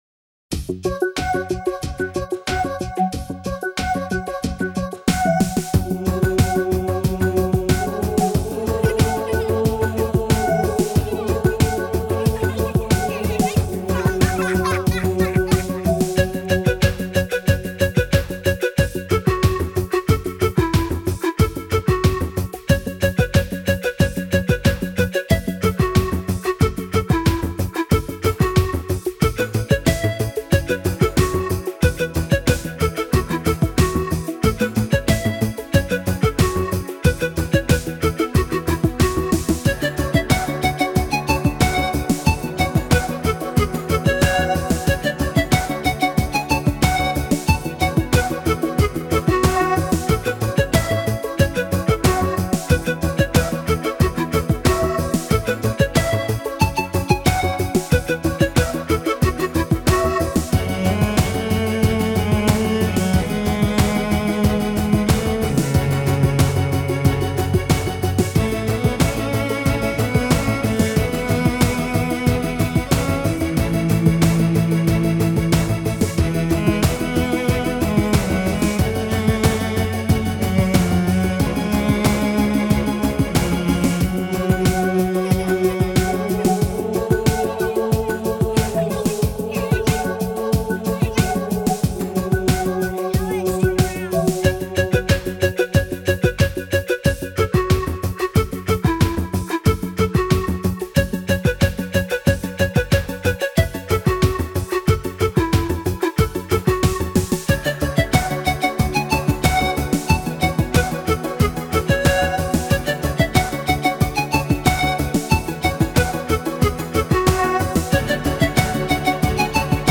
Жанр: Instrumental